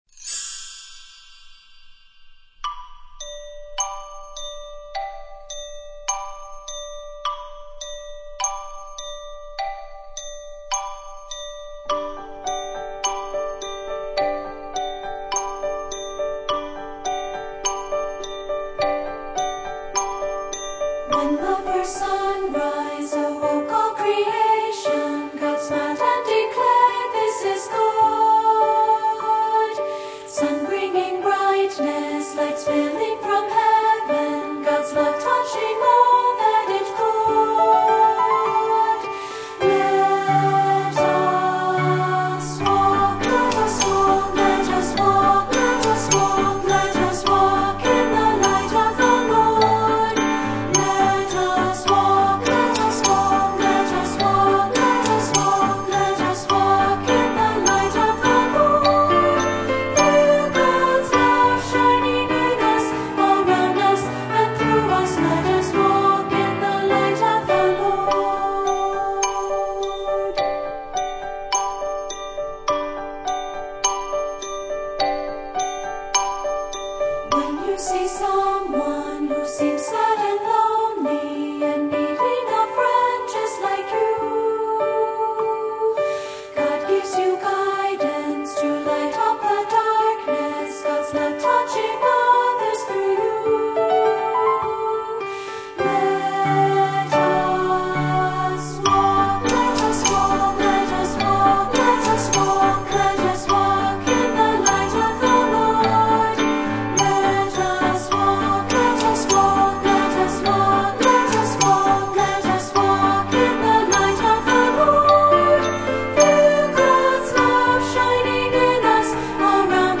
Voicing: Unison